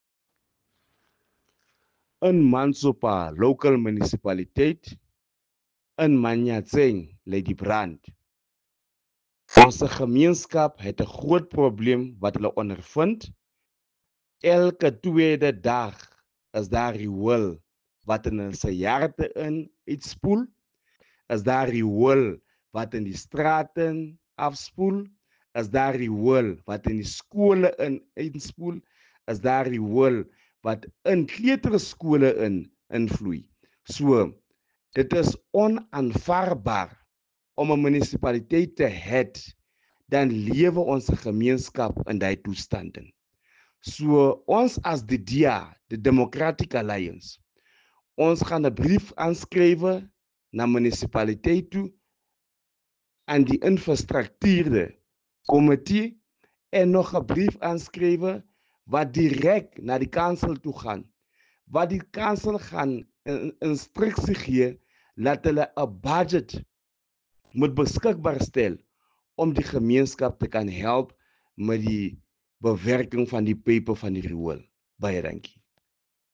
Sesotho soundbites by Cllr Nicky van Wyk.